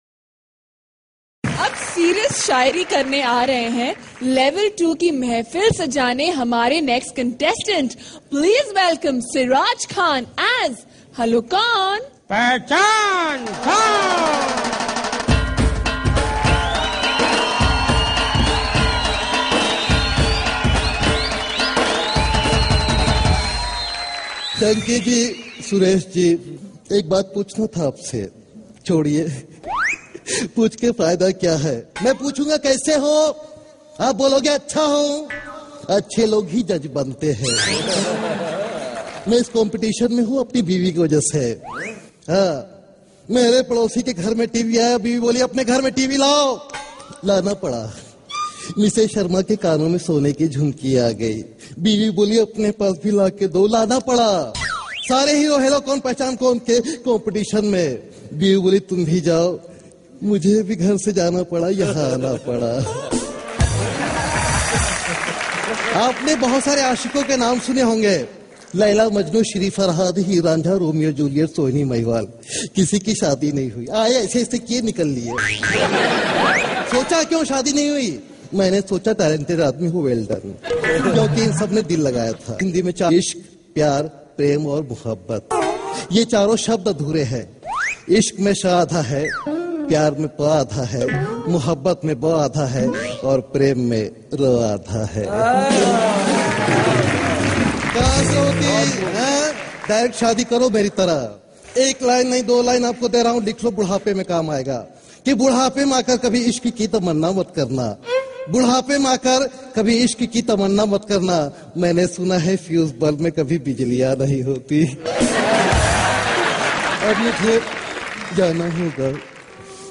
Funny Mimicry Voice